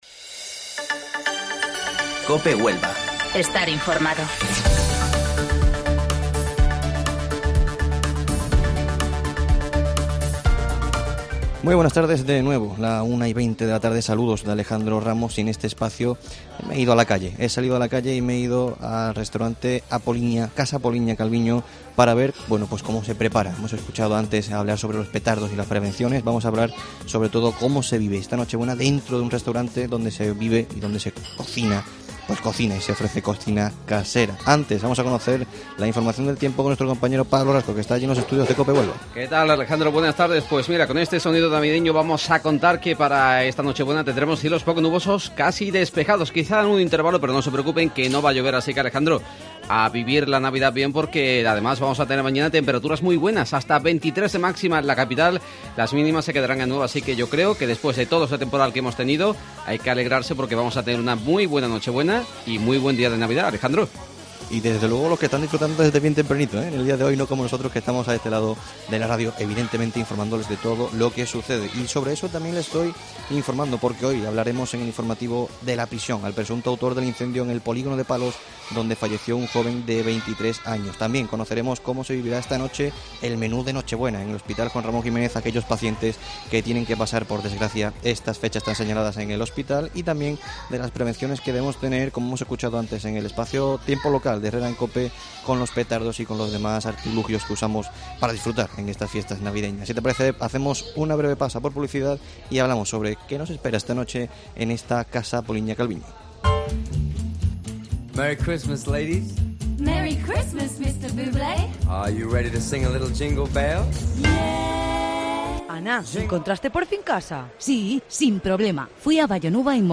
Especial Nochebuena Mediodía COPE desde Casa Apoliña Calviño
AUDIO: Hasta Casa Apoliña Calviño nos hemos desplazado hoy para ver cómo estaba la cocina y el ambiente para disfrutar de esta Nochebuena de 2019.